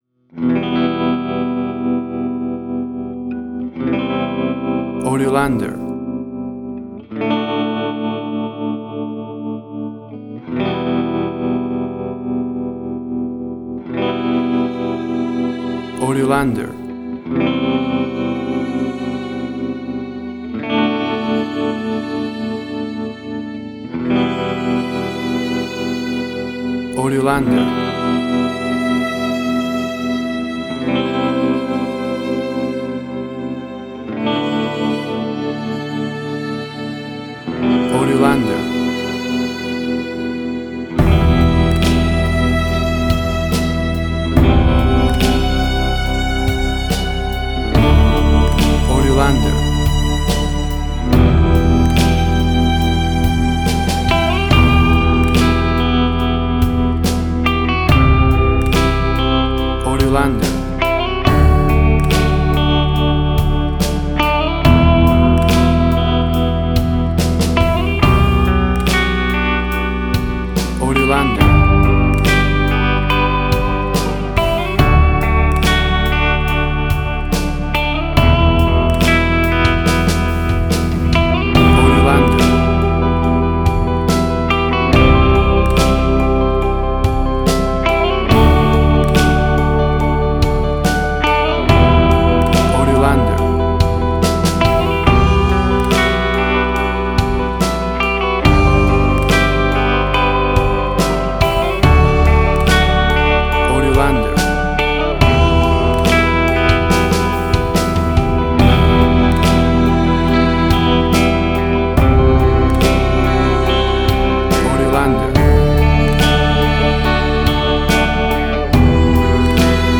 Suspense, Drama, Quirky, Emotional.
Tempo (BPM): 71